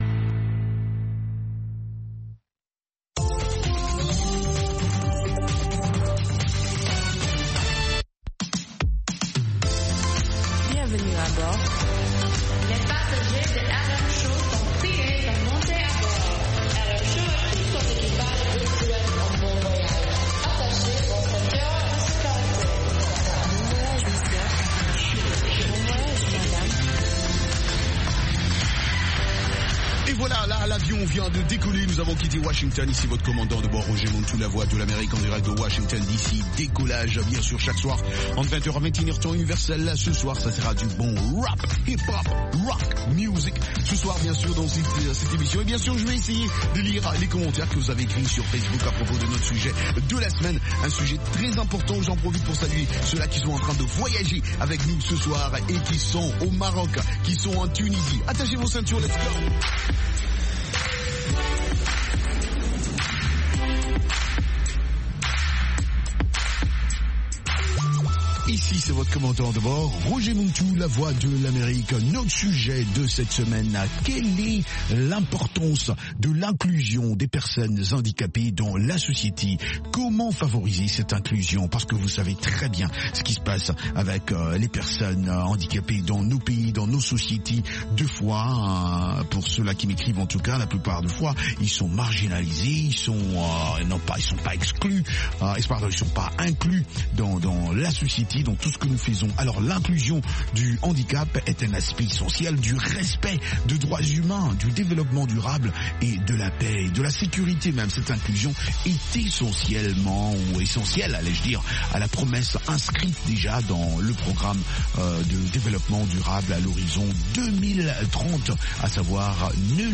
Cette émission est interactive par téléphone.